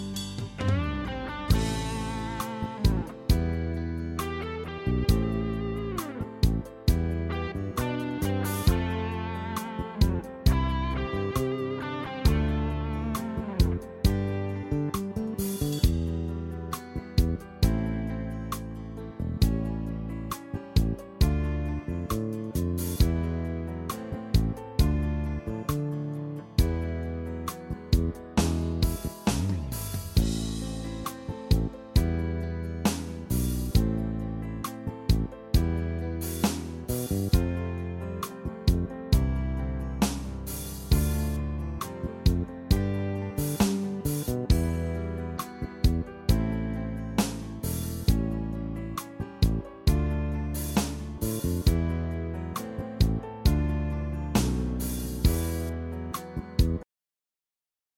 רמז: שיר של מקהלת ילדים בחו"ל